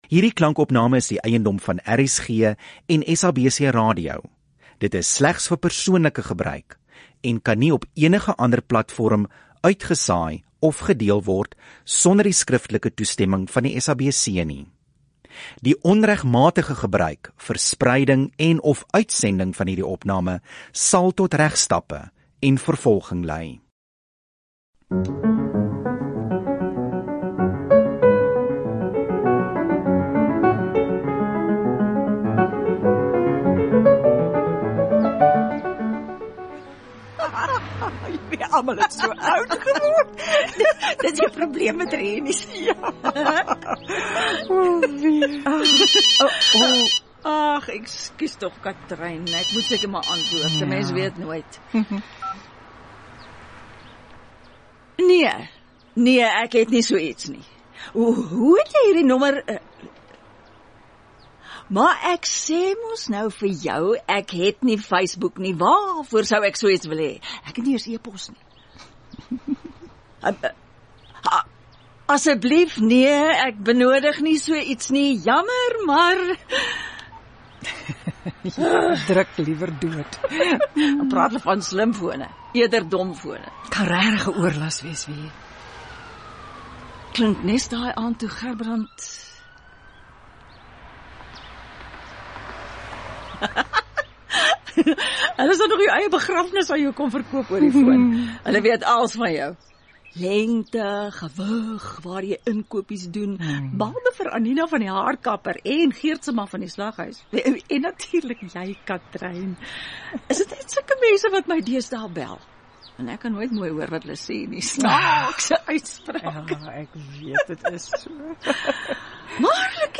Ons nuwe radioteaterseisoen begin op 7 Oktober met ‘n radioverwerking van die solostuk Klipgebede, deur Schalk Schoonbie.
‘Klipgebede’ is ‘n kragtige teaterstuk wat in die formaat van ‘n solo-produksie aangebied is en nou die eerste keer op radio gehoor kan word.